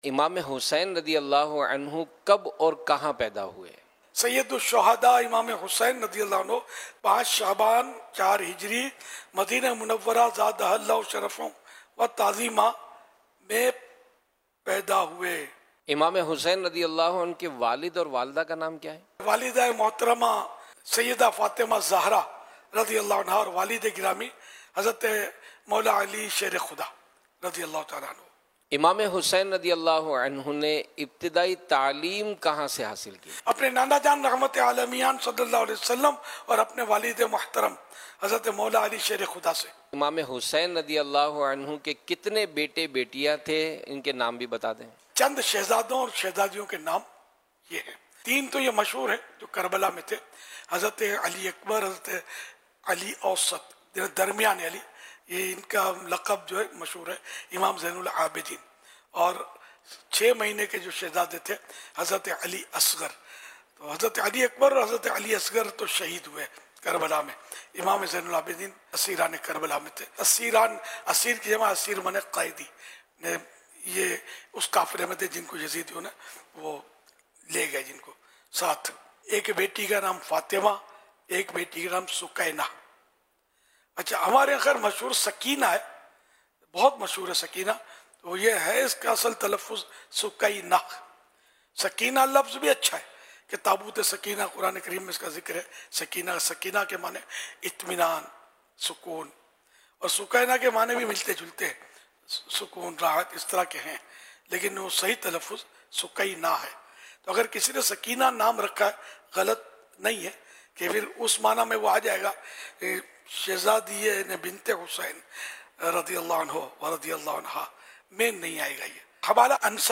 امام حسین رضی اللہ عنہ کی سیرت سے متعلق سوال و جواب